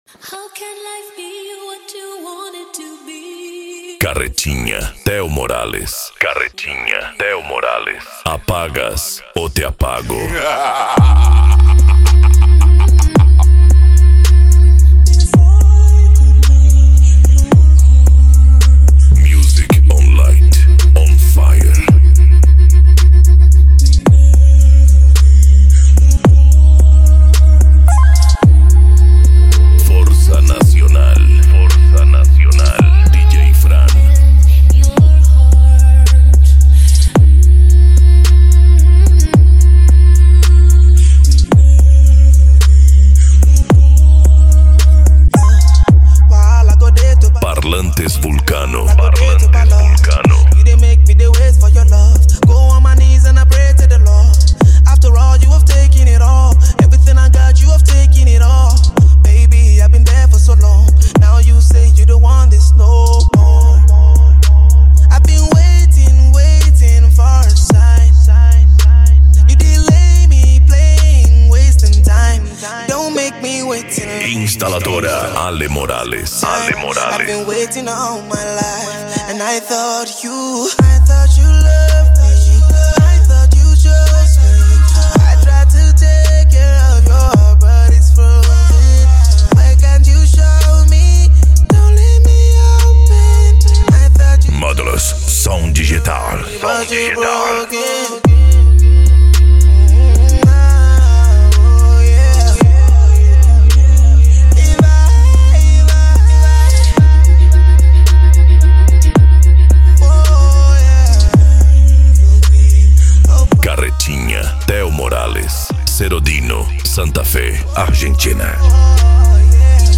Psy Trance
Remix